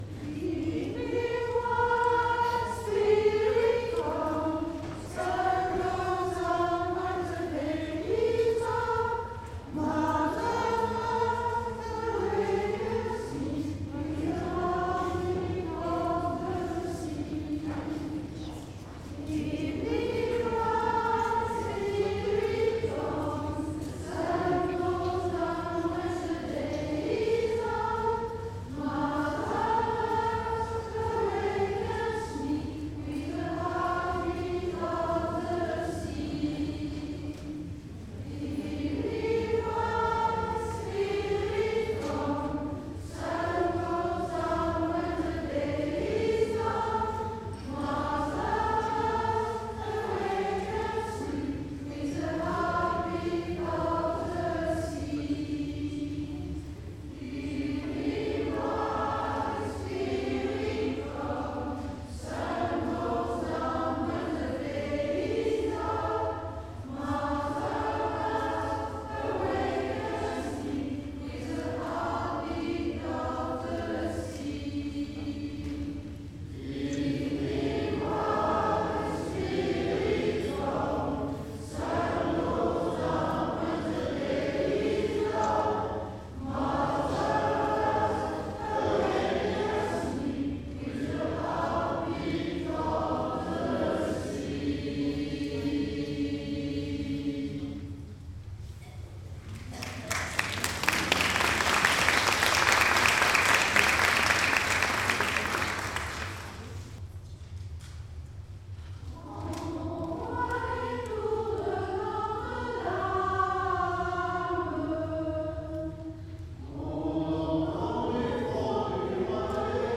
Concert de Noël Chapelle d'Arliquet (Aixe-sur Vienne) Enregistrement du concert